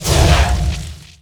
bullet_flame1.wav